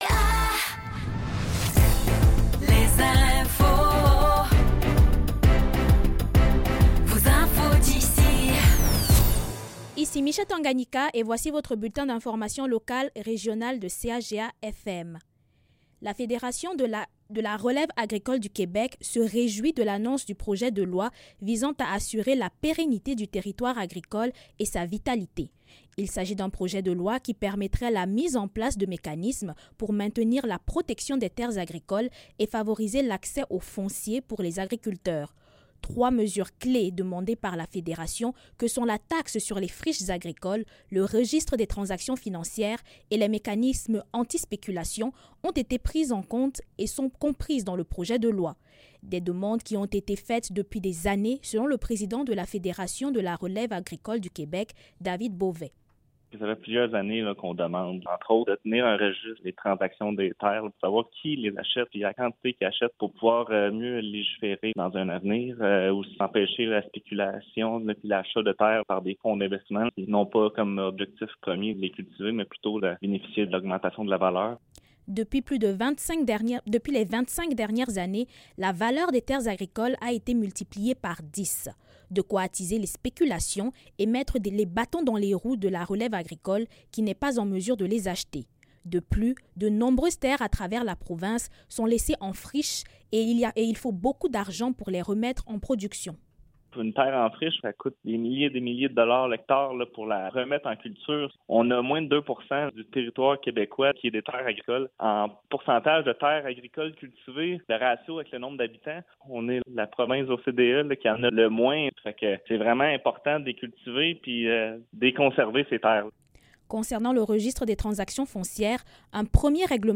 Nouvelles locales - 12 décembre 2024 - 15 h